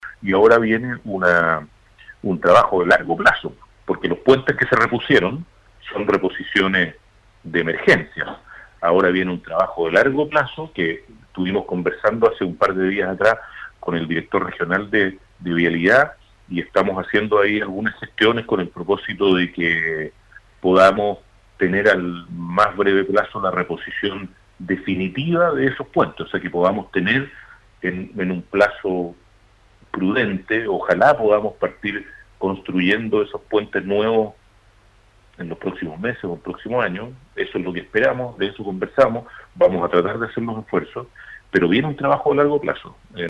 La autoridad manifestó que aún falta reponer los tres puentes que destruyó el aluvión de mayo pasado, por lo que de aquí en adelante viene un trabajo de largo plazo de parte del ministerio de Obras Públicas.